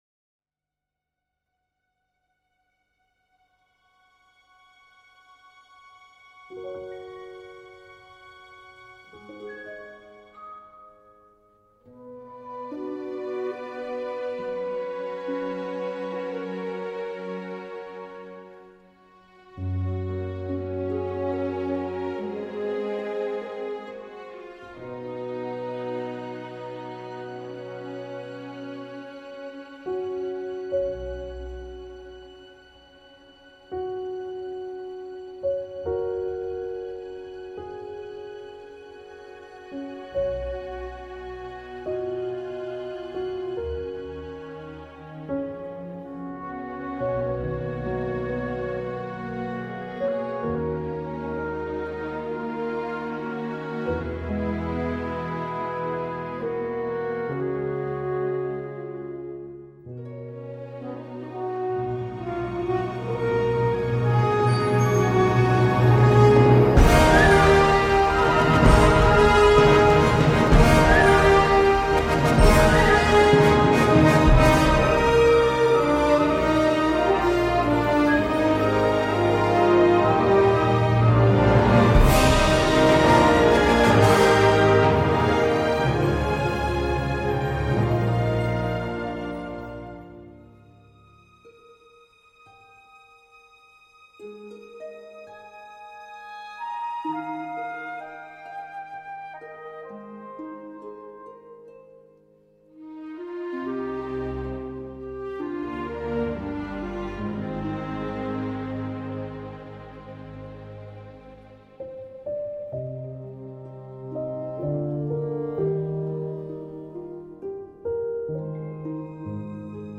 orchestration aérée et lisible.
Ici, il propose une musique charmante, sensible et féerique.
La suite finale a néanmoins du panache.